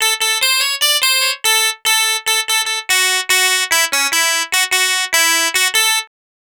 Twisting 2Nite 1 Clav-A.wav